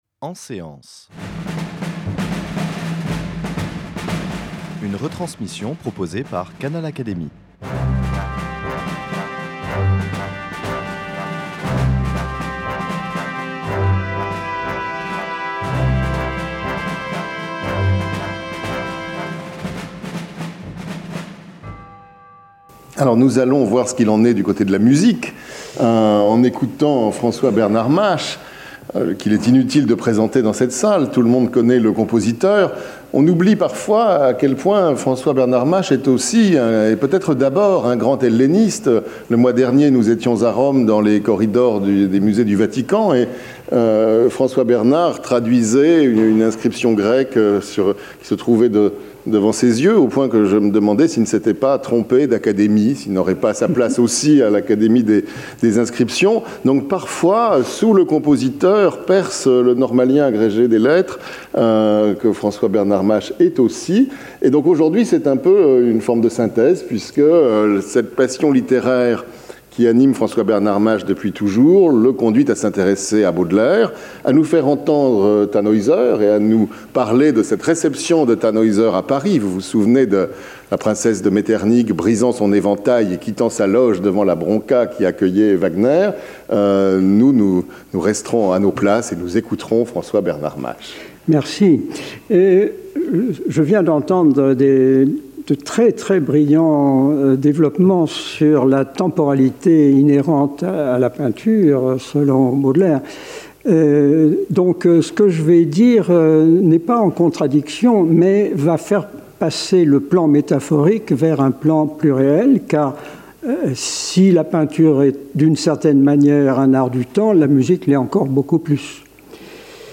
L’Académie des beaux-arts a organisé une journée de Rencontres dédiée à « Baudelaire et les arts », le mercredi 16 mai au Palais de l’Institut de France, sous la Présidence de Messieurs Patrick de Carolis (matin) et Adrien Goetz (après-midi), membres de l’Académie.